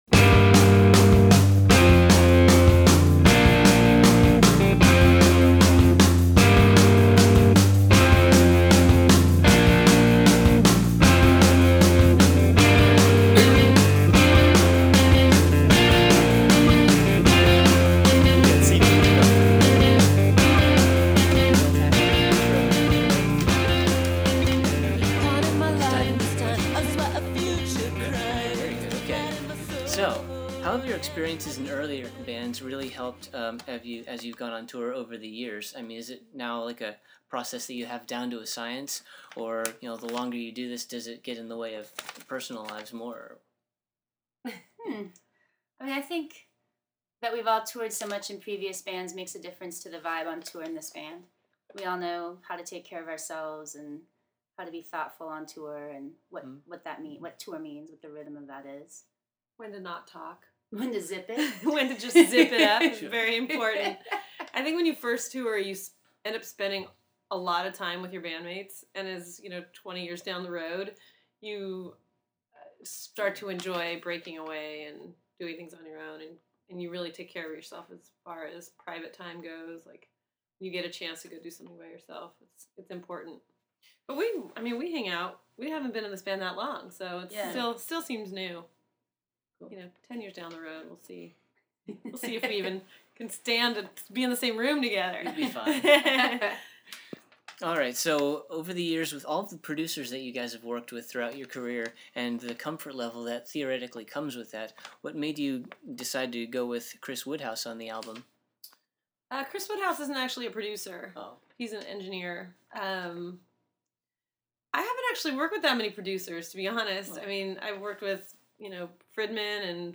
Exclusive: Wild Flag Interview
15-interview-wild-flag.mp3